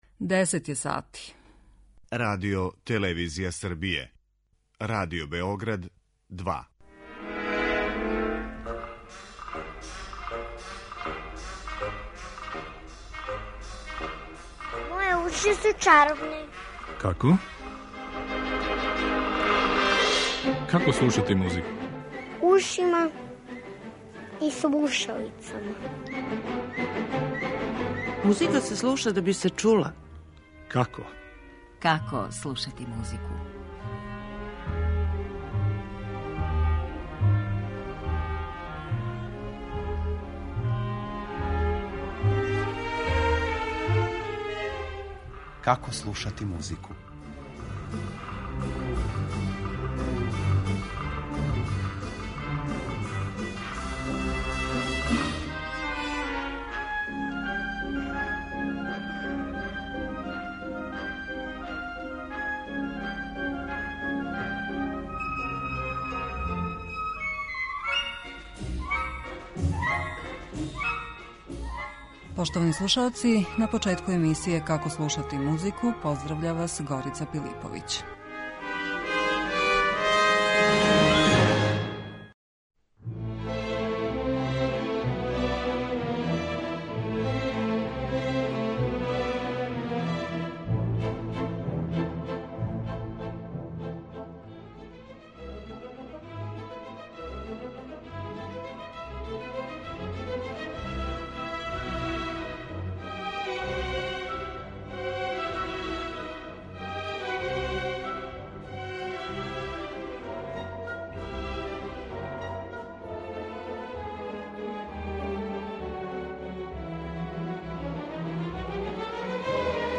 И друга емисија циклуса почиње Валцером из музике за драму Маскерада Михаила Љермонтова, музике коју је компоновао Арам Хачатурјан.